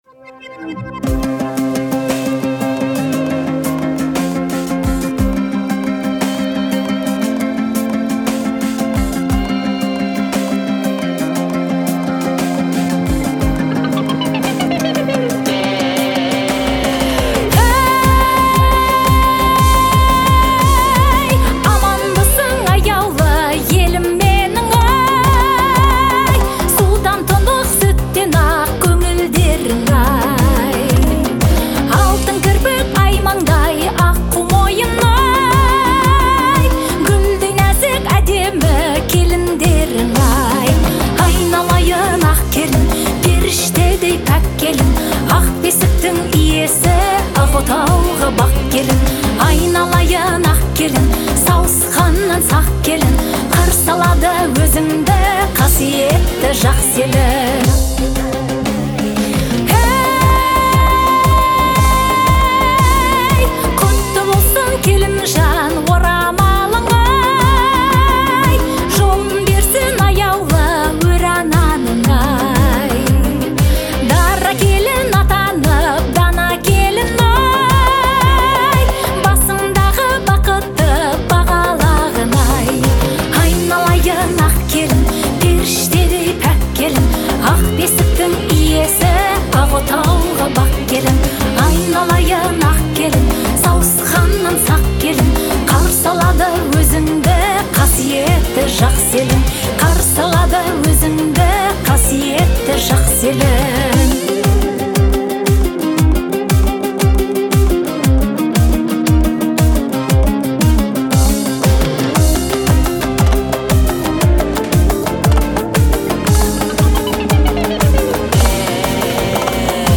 Жанр — этно-поп, а настроение — лиричное и меланхоличное.